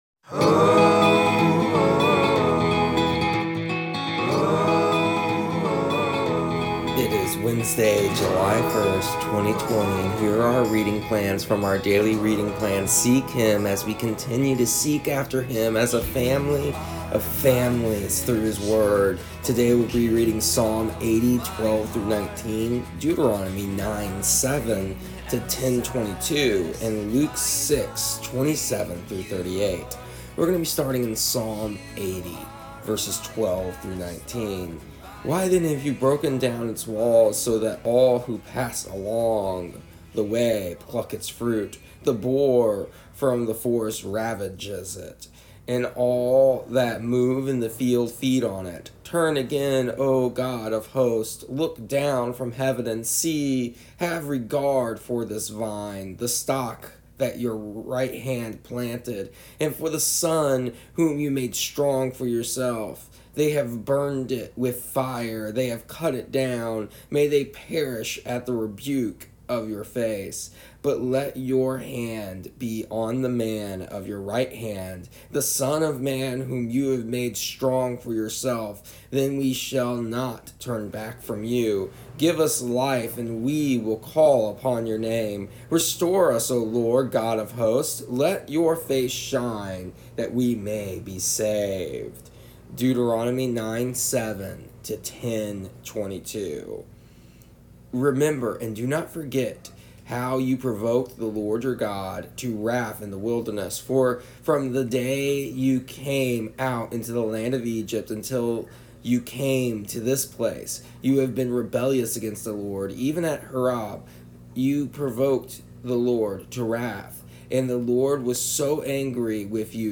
Here is an audio version of our daily readings from our daily reading plan Seek Him for July 1st, 2020. Today we discuss why Moses recaps all the history of the people of Israel for the next generation and how it is to remind them of who they are.